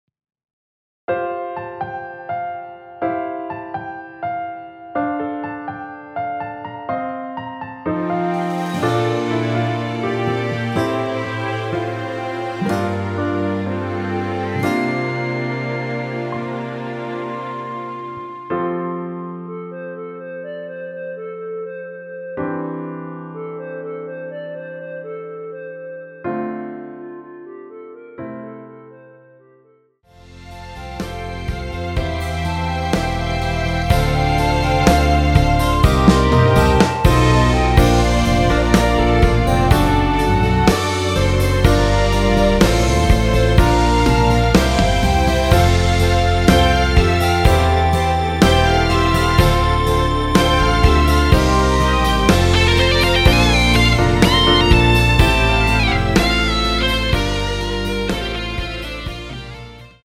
원키에서(+4)올린 멜로디 포함된 MR입니다.(미리듣기 확인)
앞부분30초, 뒷부분30초씩 편집해서 올려 드리고 있습니다.